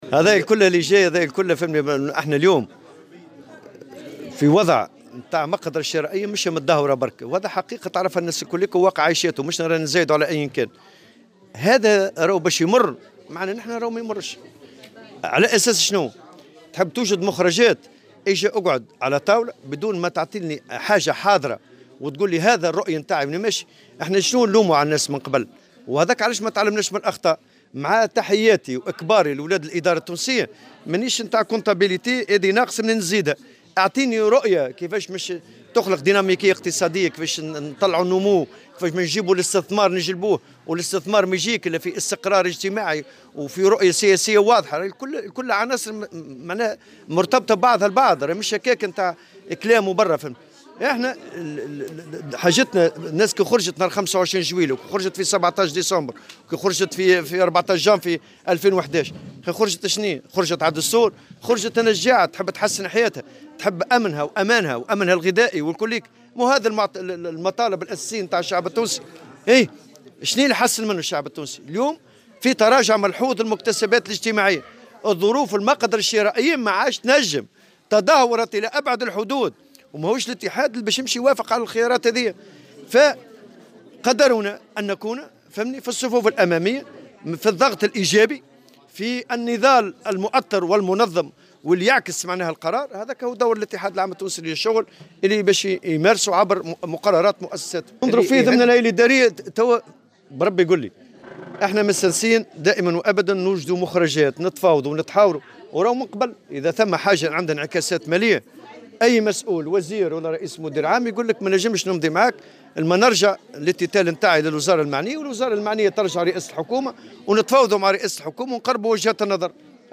وأكد في تصريح اليوم لمراسلة "الجوهرة أف أم" على هامش المؤتمر العادي للمكتب الوطني للمرأة العاملة، المُنعقد بمدينة الحمامات، أن الوضع الاقتصادي والاجتماعي في تونس صعب للغاية، معبرا عن أمله في أن تُشكل الرزنامة التي وضعها رئيس الجمهورية متنفسا لتونس وشعبها.